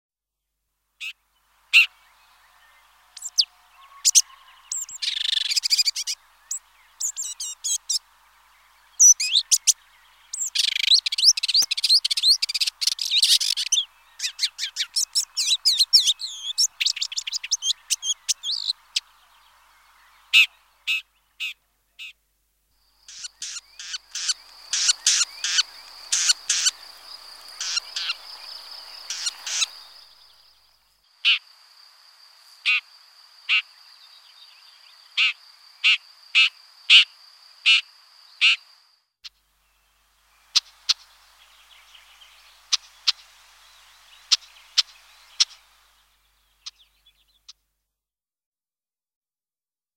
Cliquez sur lecture pour écouter le chant : Pie grièche écorcheur
86-Pie-grieche-ecorcheur.mp3